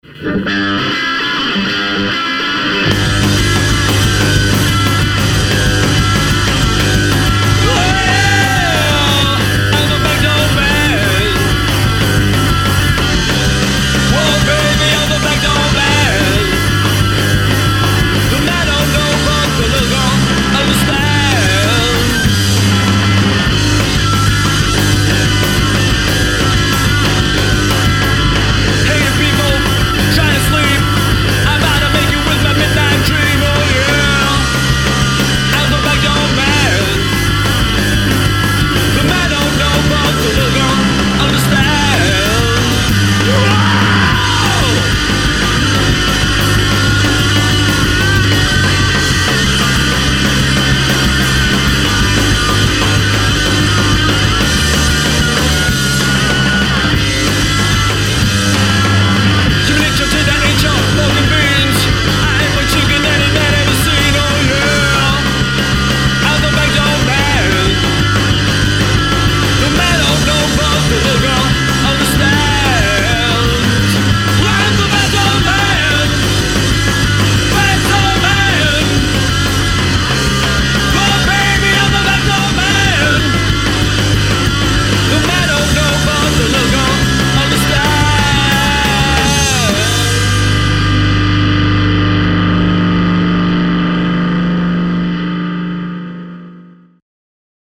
bubnjevi
gitara, vokal